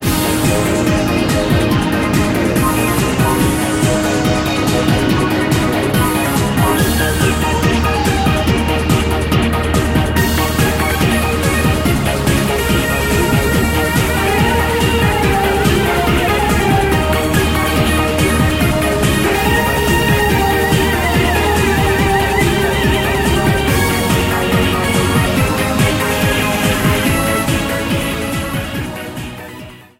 Background music